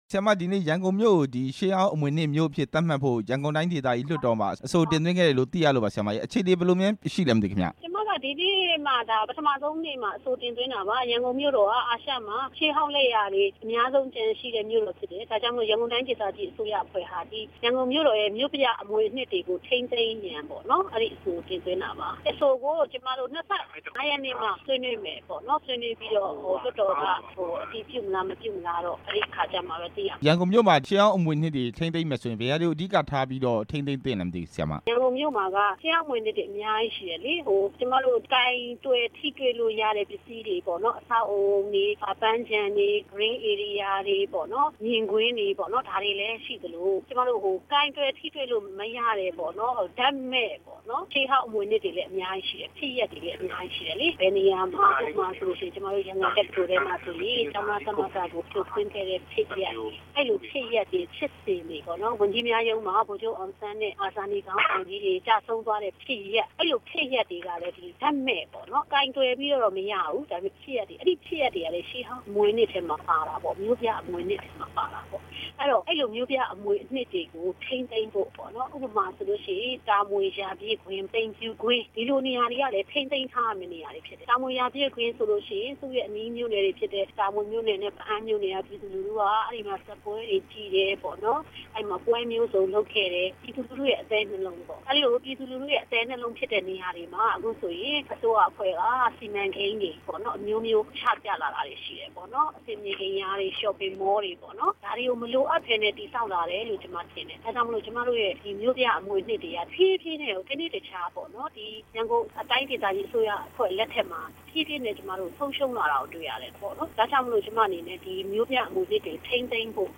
ဒေါက်တာညိုညိုသင်းနဲ့ မေးမြန်းချက်